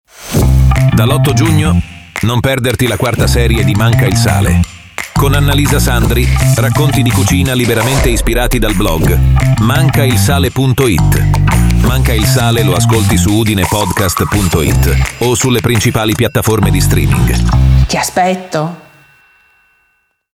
MANCAilSALE-PROMO_4_stagione.mp3